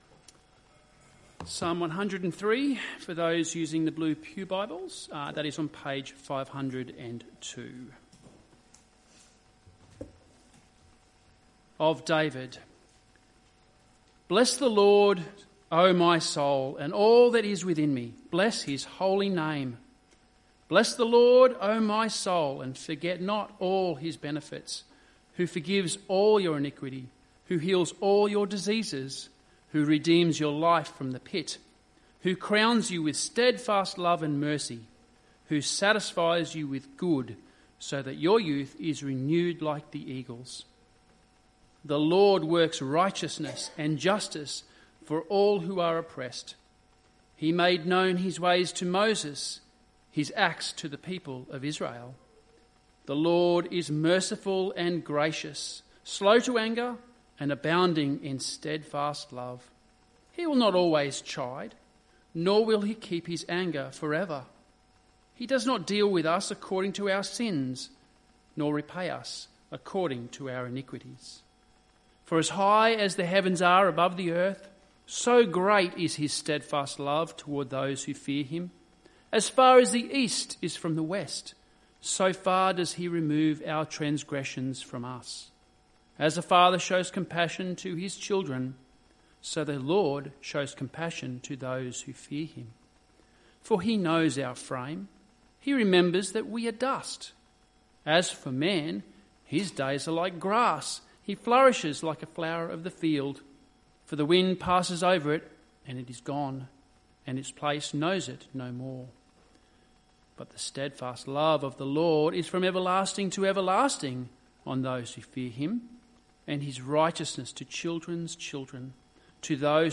Nov 23, 2025 Psalm 134 – Blessings FROM Zion MP3 SUBSCRIBE on iTunes(Podcast) Notes Sermons in this Series Readings: Psalm 103:1-22 Psalm 134 Psalm 134 – Blessings FROM Zion Bless You!